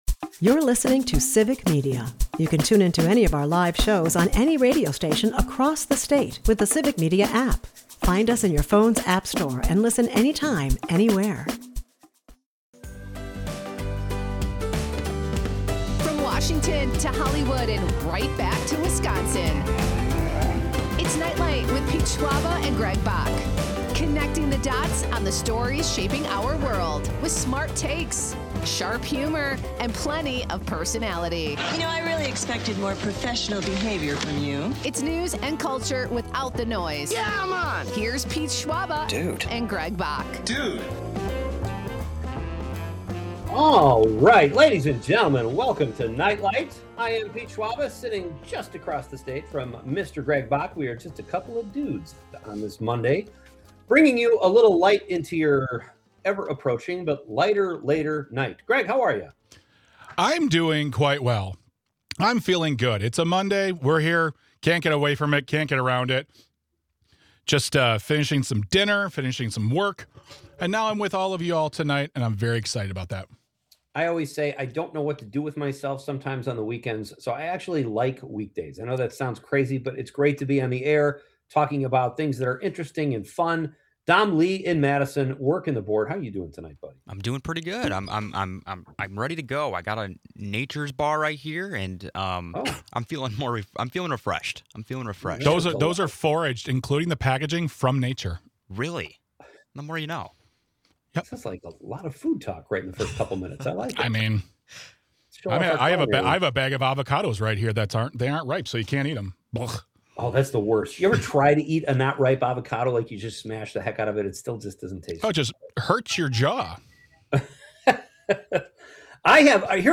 Guests: Cathy O'Neil